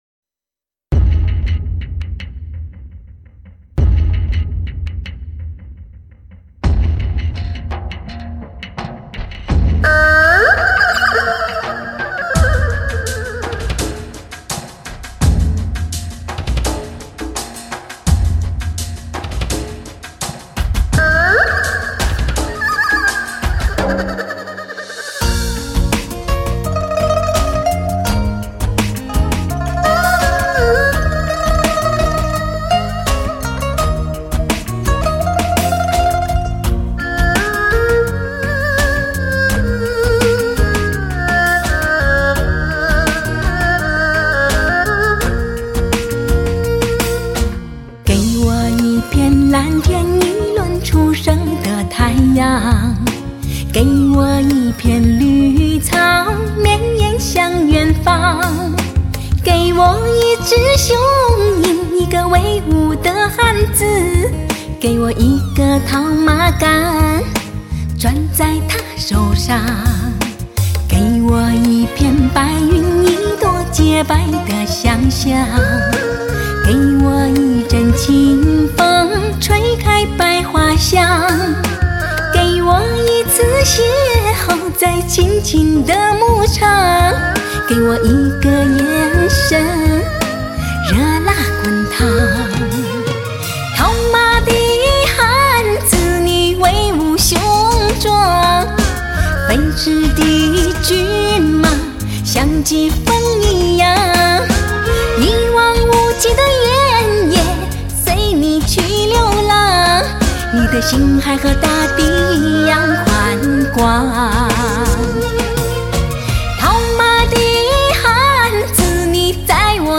HI-FI发烧高保真新民歌民乐真情系列，立体声录音制式，同步收音超级现场，新世纪发烧美丽女声，重温往日感动好时光。